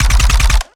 GUNAuto_RPU1 B Burst_06_SFRMS_SCIWPNS.wav